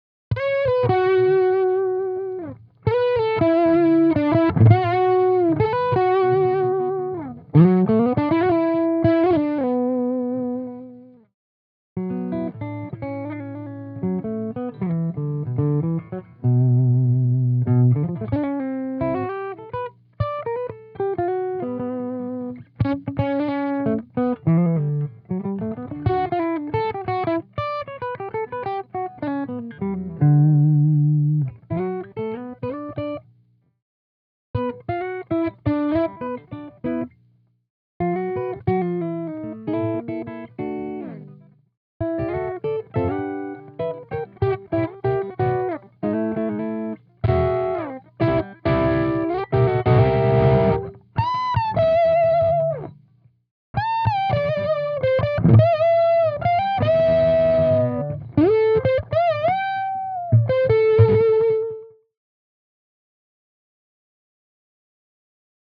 Gold Pedal Demo Redo
It doesn’t really fit the metal lead that I did, and this demo is bluesier. You can hear me roll down my guitar’s volume at around 10 seconds and bring it up again at around 50 seconds. I tried to show off how it responds to volume tone changes.
Gold pedal without boost, EQ